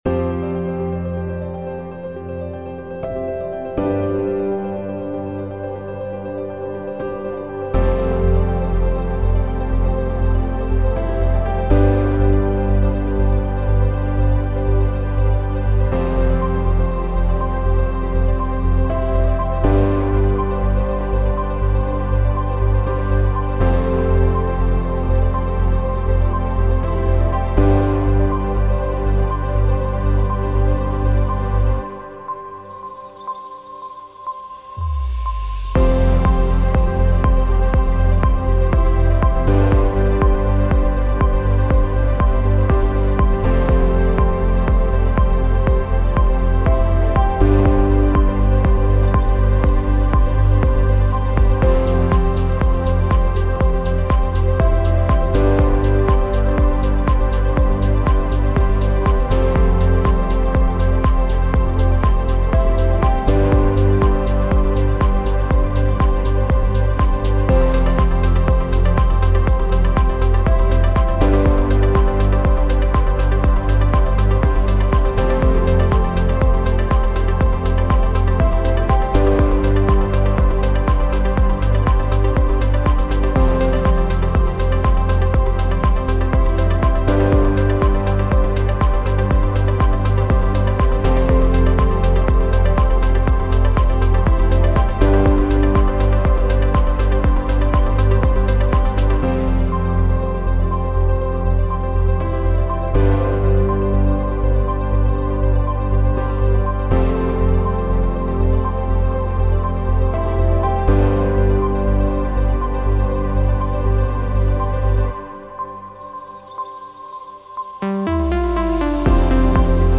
Drum, Piano, Synth